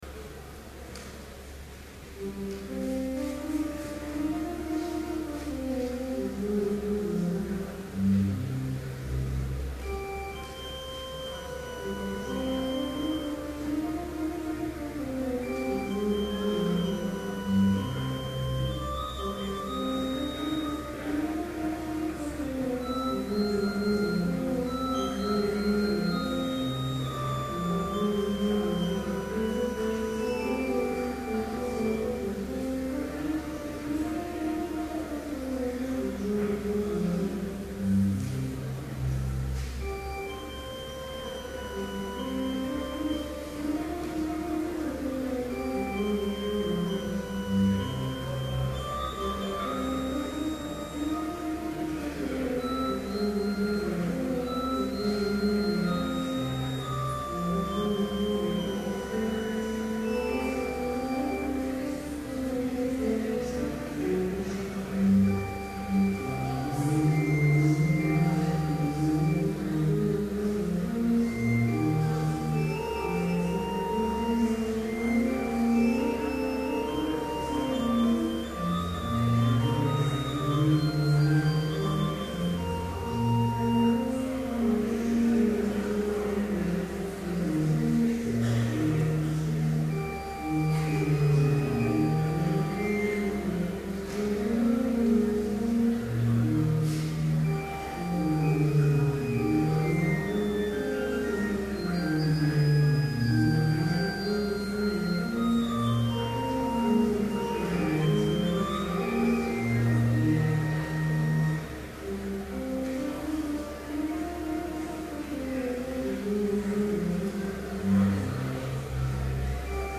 Complete service audio for Chapel - March 22, 2012